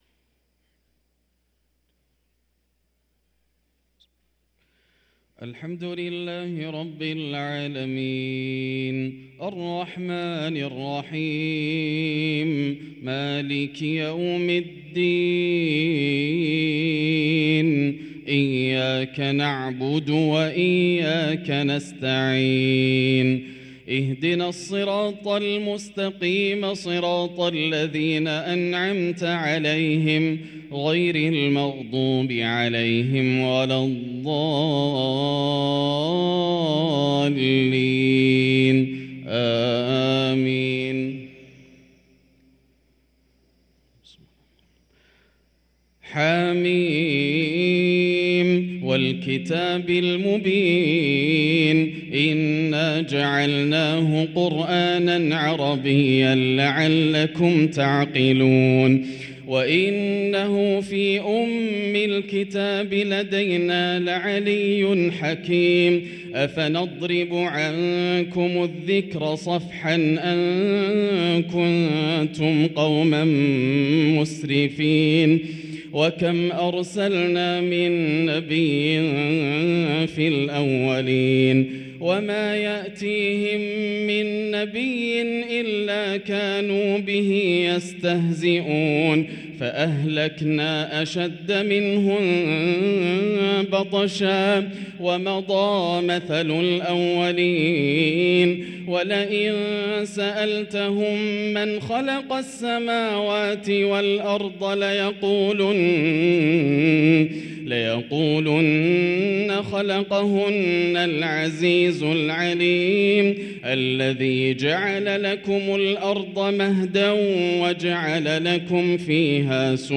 صلاة الفجر للقارئ ياسر الدوسري 26 شعبان 1444 هـ
تِلَاوَات الْحَرَمَيْن .